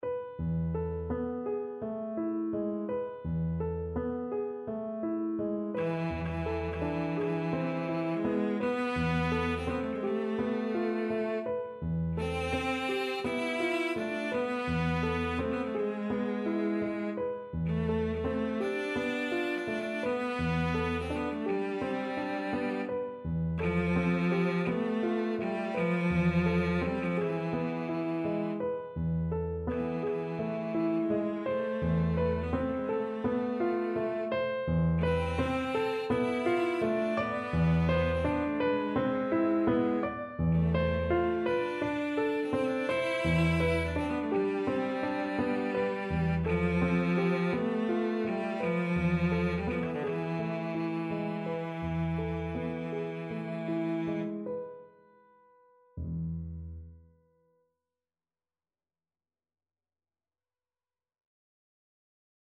Calmo e sostenuto =42
2/4 (View more 2/4 Music)
Classical (View more Classical Cello Music)